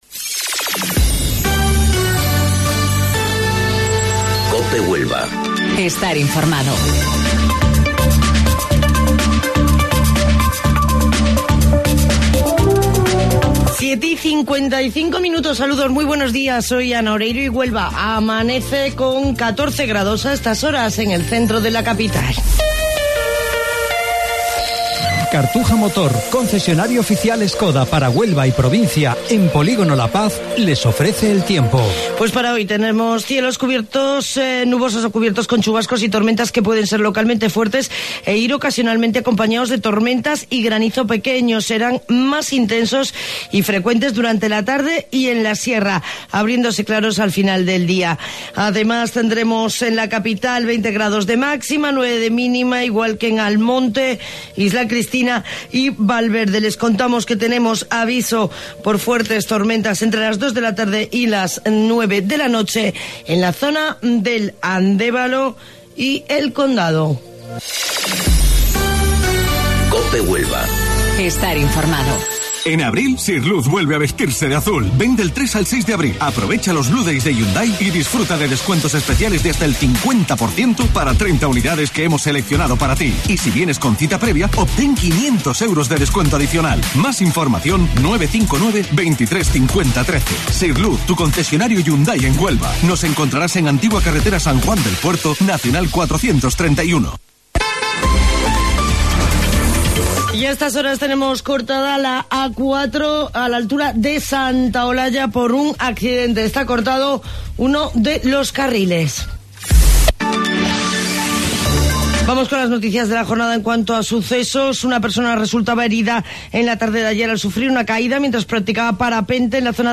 AUDIO: Informativo Local 07:55 del 1 de Abril